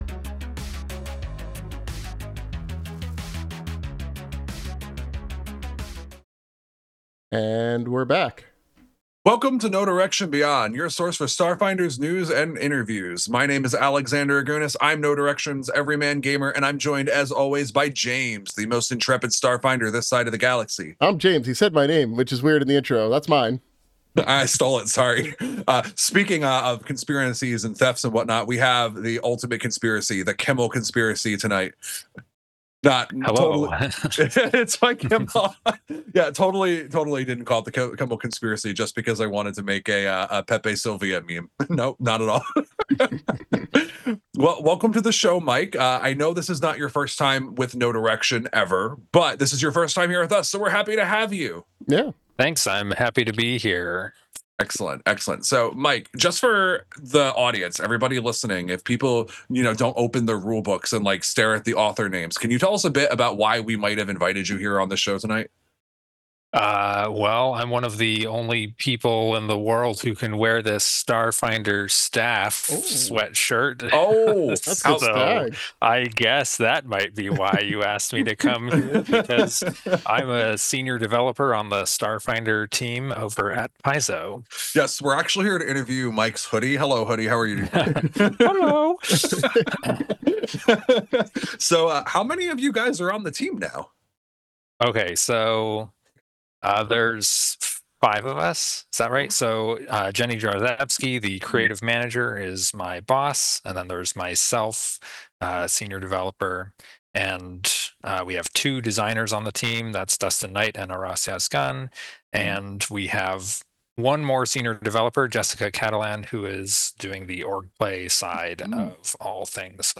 Recorded live on Twitch.